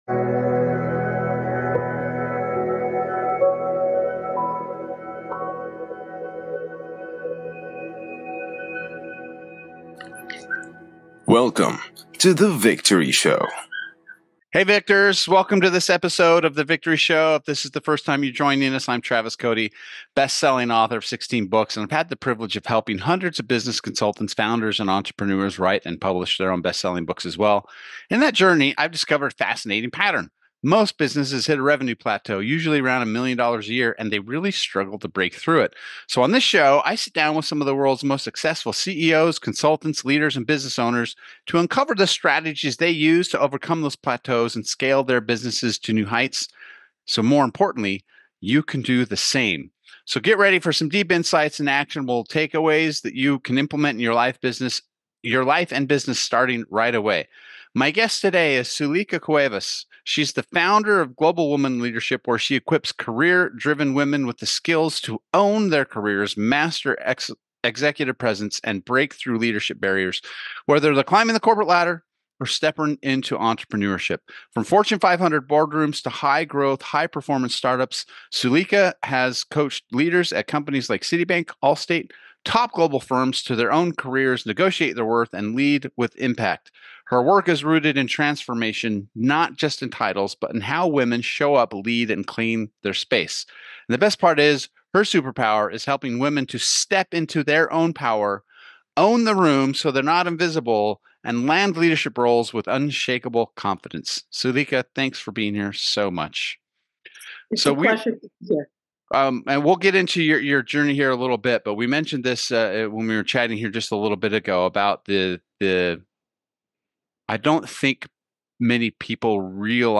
This conversation is packed with frameworks, strategies, and mindset shifts that accelerate careers and transform how women show up in any room.